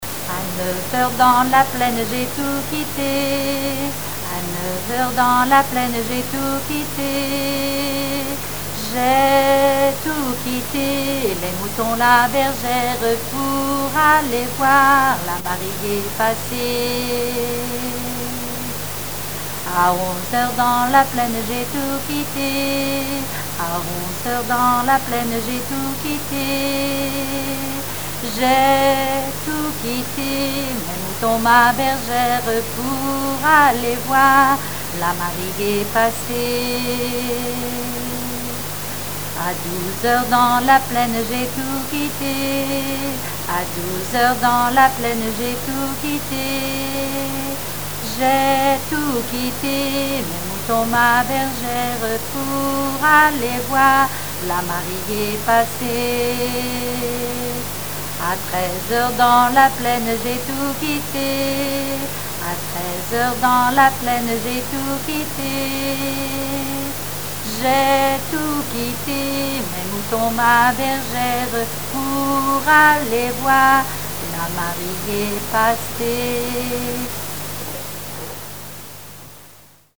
gestuel : à marcher
Genre énumérative
répertoire de chansons populaire et traditionnelles
Pièce musicale inédite